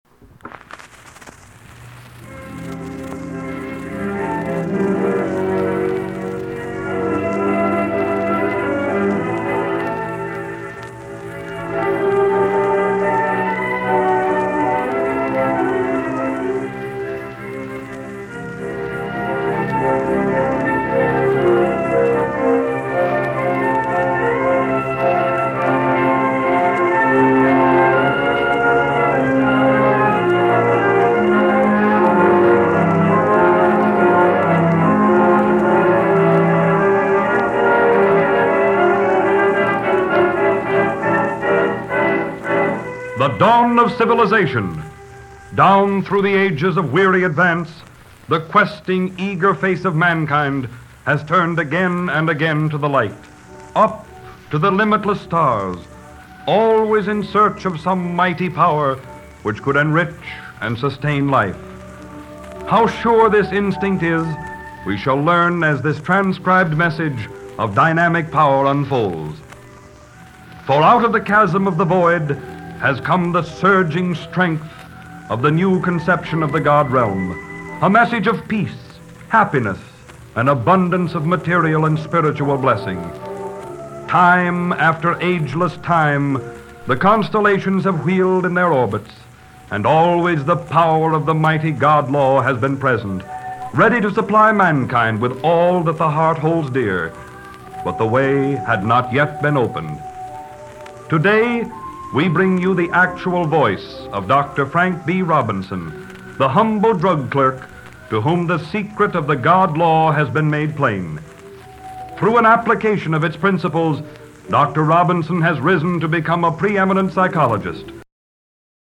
Also includes a testimonial from a man claimed to live in Holland.